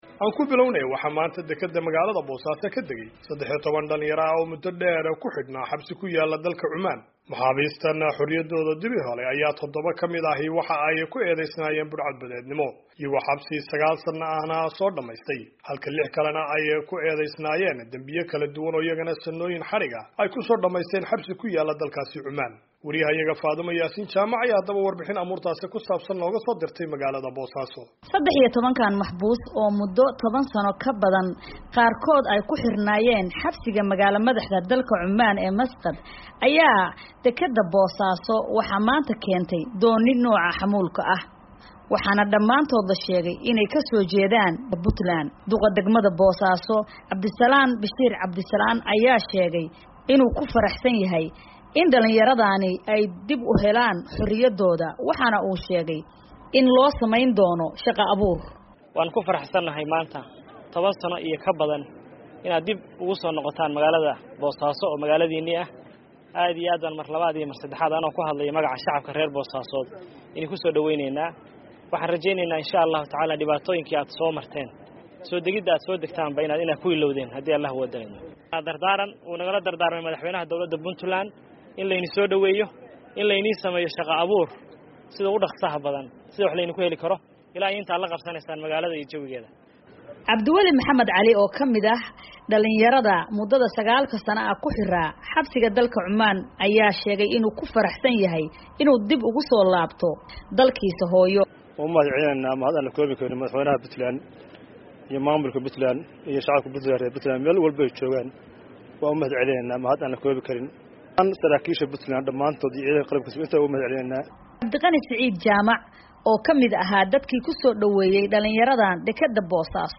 ayaa warbixintaan nooag soo dirtay Boosaaso.